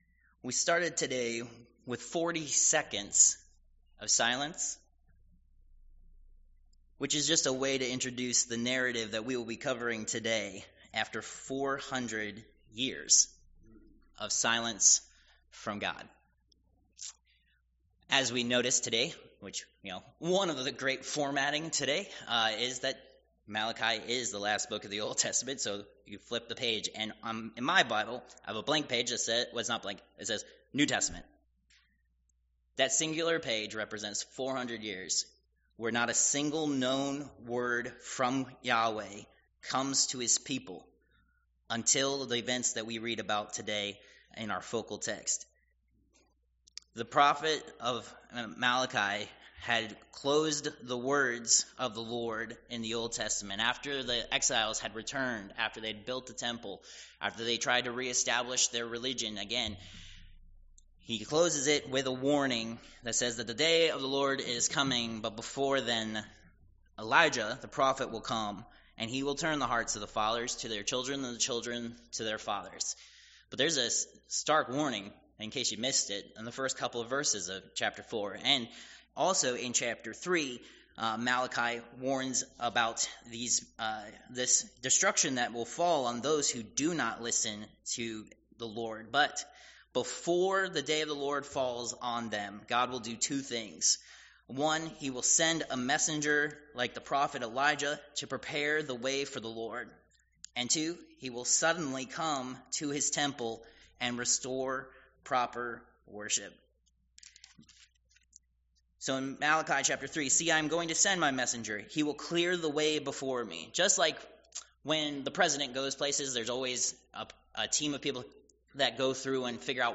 Passage: Luke 1:5-25, 57-80 Service Type: Worship Service